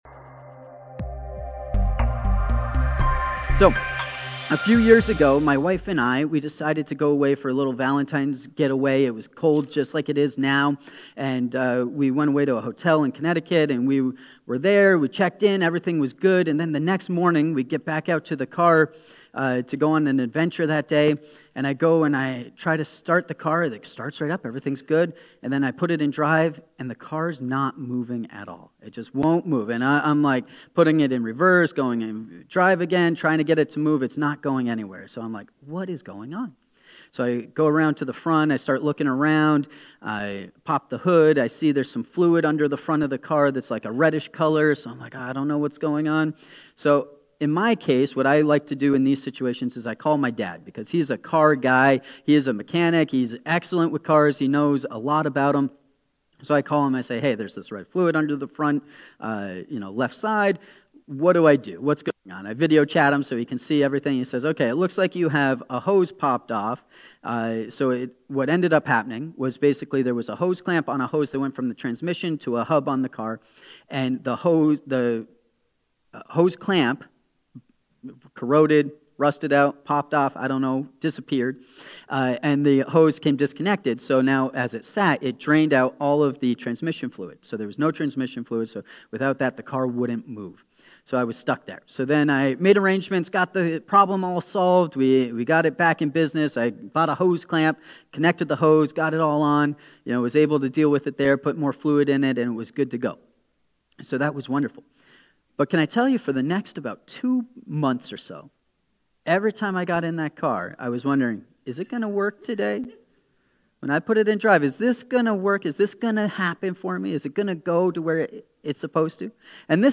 Faithful-To-The-End-Sermon.mp3